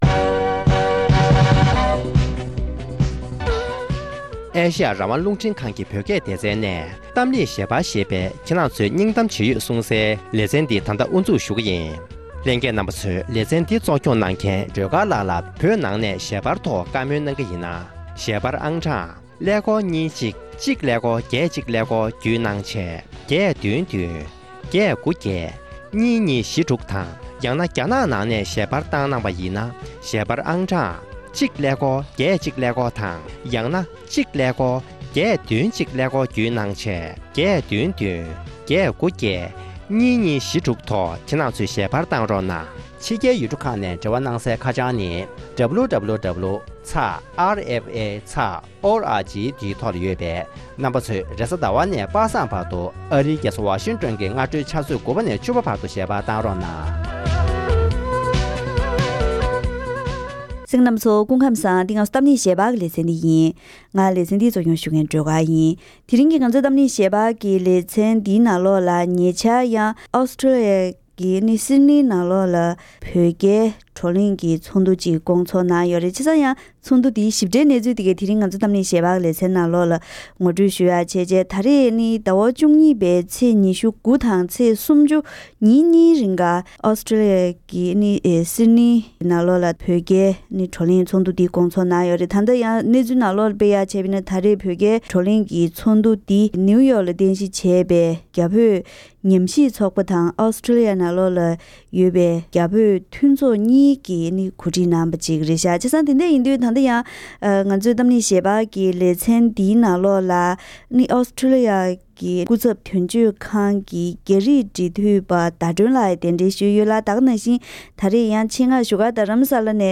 ༄༅། །དེ་རིང་གི་གཏམ་གླེང་ཞལ་པར་ལེ་ཚན་ནང་། ནིའུ་ཡོཀ་ཏུ་རྟེན་གཞི་བྱས་པའི་རྒྱ་བོད་ཉམས་ཞིབ་ཚོགས་པ་དང་ཨོ་སི་ཊོ་ལི་ཡའི་རྒྱ་བོད་མཐུན་ཚོགས་གཉིས་ནས་གོ་སྒྲིག་འོག རྒྱལ་སྤྱིའི་རྒྱ་བོད་བགྲོ་གླེང་ཚོགས་འདུ་ཞིག་ཕྱི་ལོ་ ༢༠༡༢ ཟླ་ ༡༢ ཚེས་ ༢༩ ནས་ཚེས་ ༣༠ བར་གྲོང་ཁྱེར་ཆེ་ཤོས་སིད་ཌི་ནི་ཡི་བེང་སི་ཊོན་ (Bankstown) ཁུལ་དུ་ཉིན་གཉིས་རིང་ཚོགས་ཡོད་པ་དེའི་སྐོར་གླེང་མོལ་ཞུས་པ་ཞིག་གསན་རོགས་གནང་།།